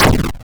Train-of-Thought - Retro 8-Bit Game Jam — May 10th - 18th 2020 (1 week)
Hit.wav